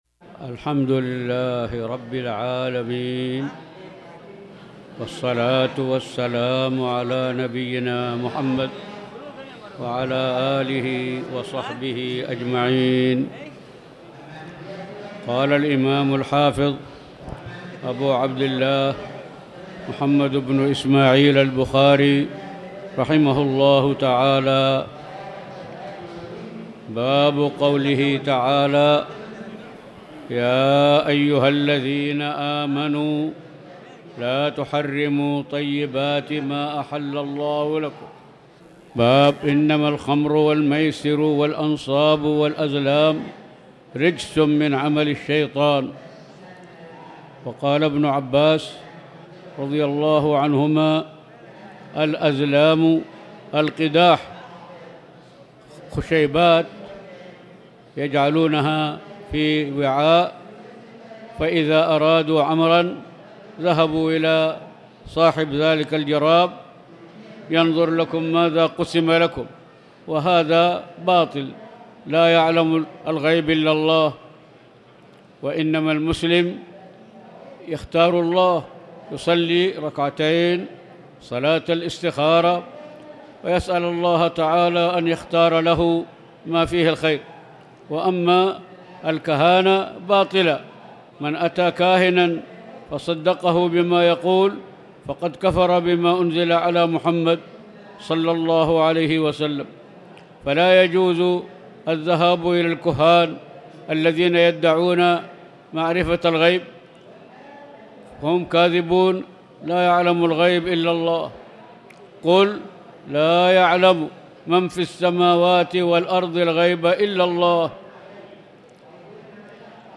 تاريخ النشر ١ صفر ١٤٤٠ هـ المكان: المسجد الحرام الشيخ